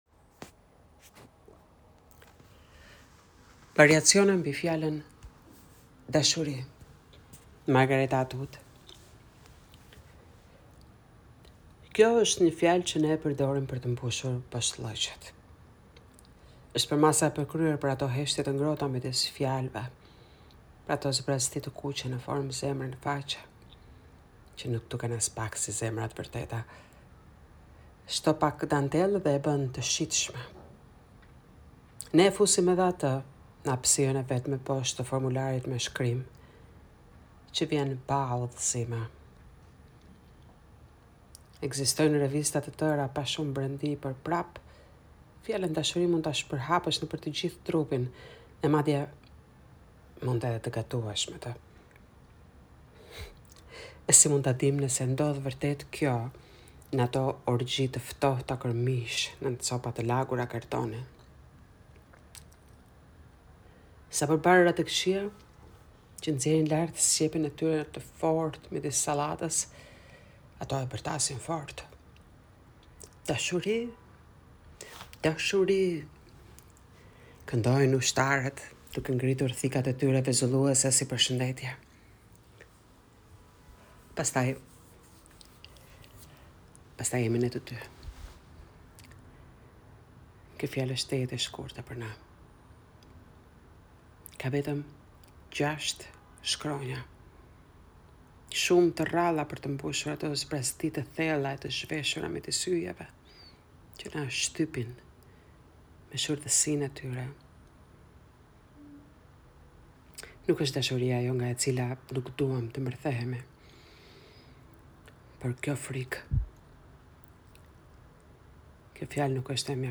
Poezia e lexuar